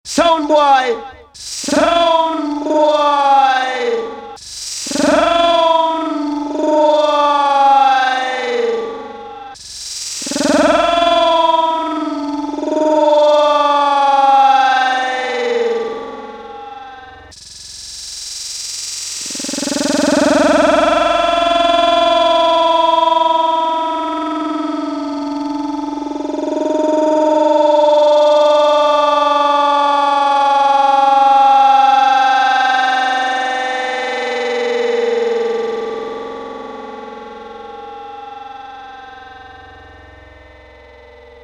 Some Akaizer for those old school Jungle time stretches